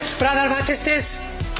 bradar Meme Sound Effect